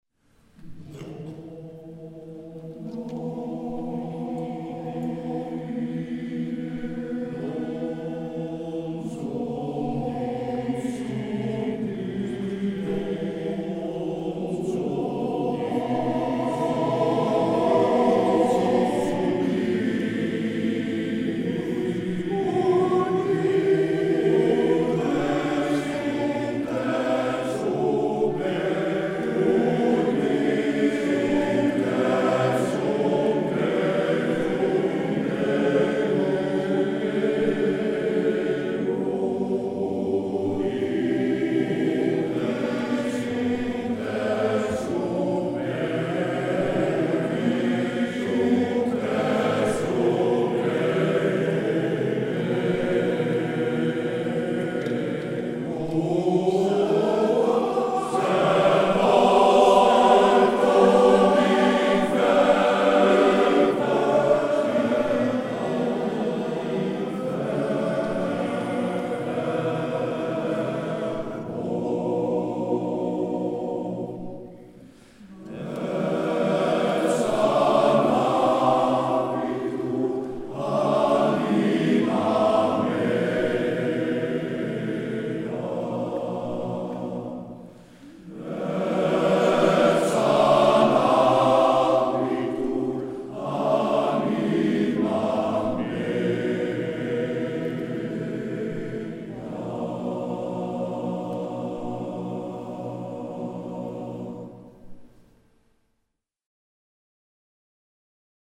Hieronder treft u een aantal muziekfragmenten aan van Mannenkoor Lambardi.